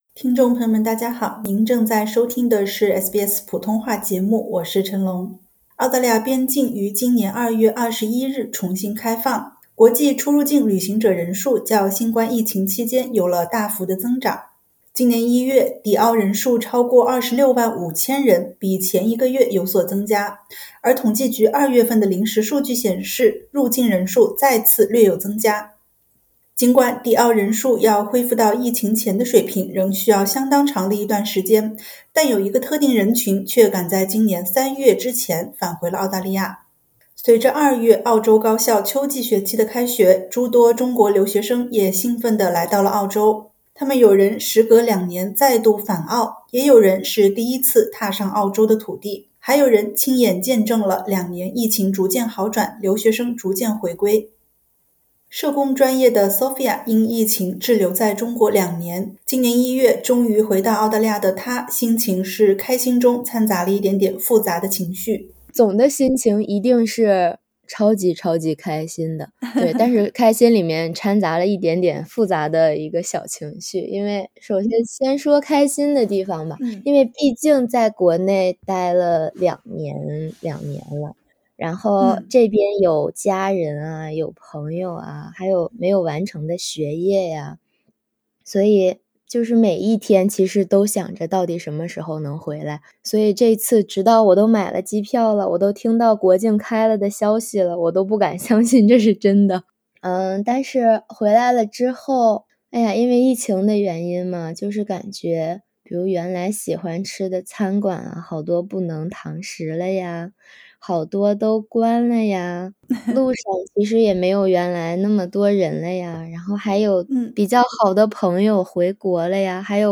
隨著二月澳洲高校秋季學期的開學，諸多中國留學生也興奮地來到澳洲。他們有人時隔兩年再度返澳，也有人是第一次踏上澳洲的土地，還有人親眼見證了兩年疫情逐漸好轉、留學生逐漸回歸。(點擊圖片收聽寀訪）